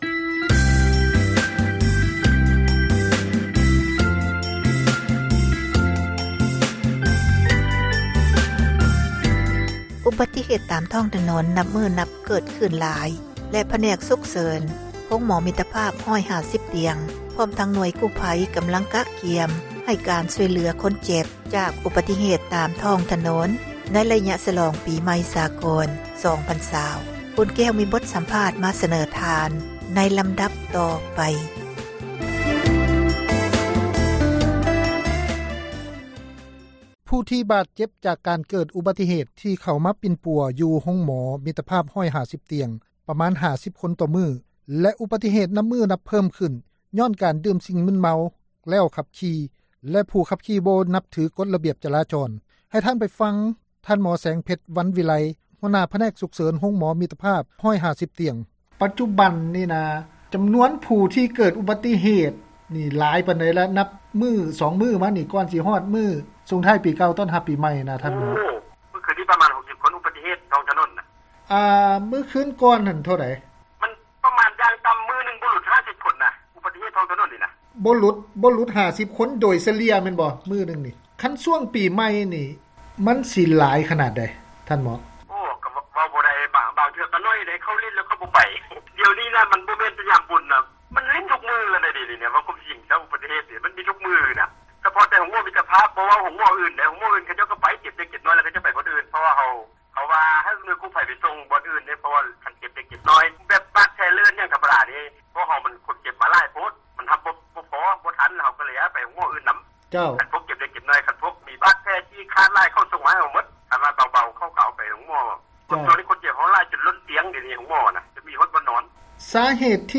ໃຫ້ທ່ານໄປຟັງ ບົດສັມພາດ ເຈົ້າໜ້າທີ່ກູ້ພັຍ ພັທນາຜາສຸຂ ເບິ່ງ. ສຽງ2: ສະເພາະ ໄລຍະການສລອງ ປີໃໝ່ ໃນປີ 2018 ທີ່ຜ່ານມາ, ມີຜູ້ເສັຽຊີວິຕ ຍ້ອນອຸບັດຕິເຫຕ 38 ຄົນ, ບາດເຈັບ 250 ຄົນ ຈາກອຸບັດຕິເຫຕ ເກີດຂຶ້ນທັງໝົດ 180 ກໍຣະນີ ທົ່ວປະເທດ.